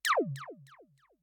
sound_laser.ogg